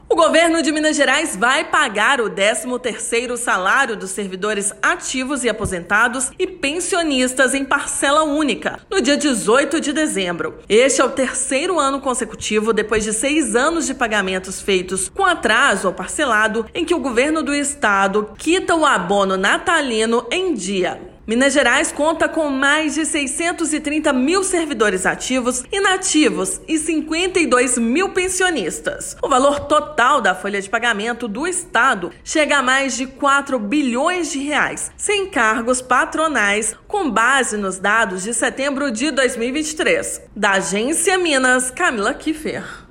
Funcionalismo receberá o benefício em parcela única. Ouça matéria de rádio.